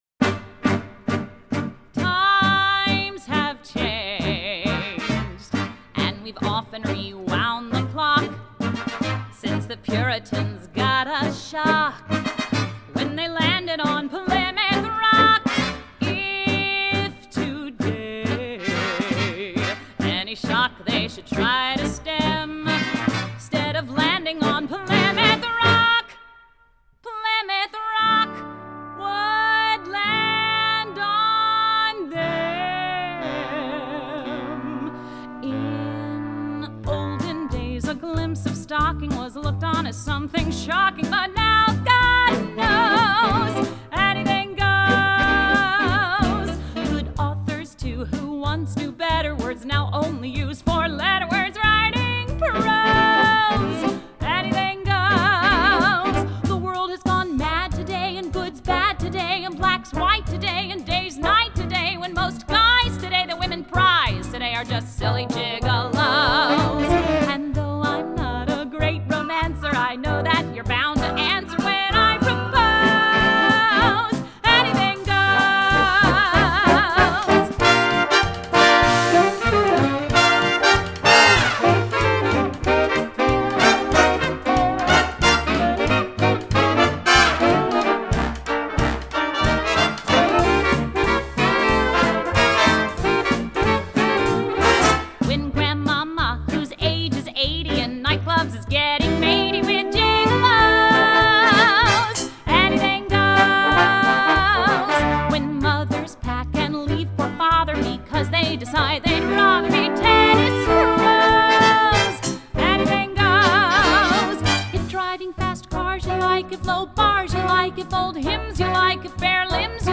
1934   Genre: Musical   Artist